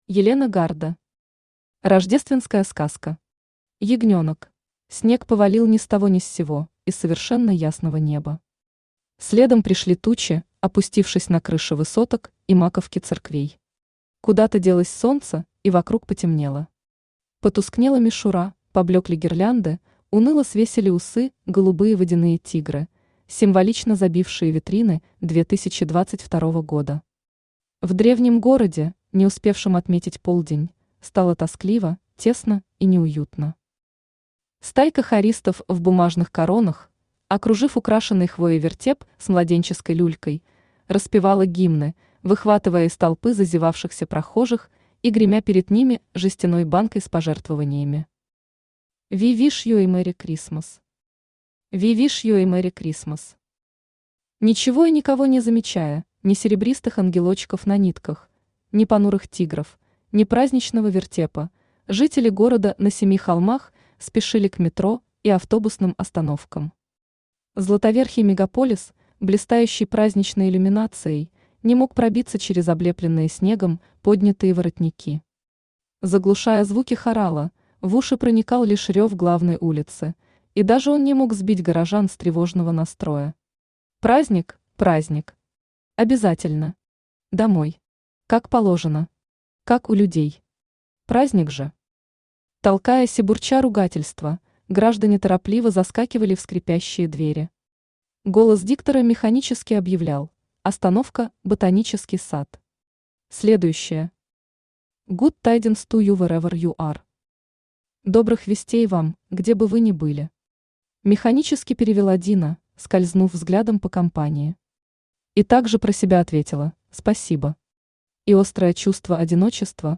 Аудиокнига Рождественская сказка. Ягнёнок | Библиотека аудиокниг
Ягнёнок Автор Елена Гарда Читает аудиокнигу Авточтец ЛитРес.